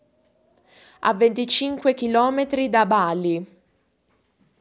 Italian_Speech_Data_by_Mobile_Phone_Reading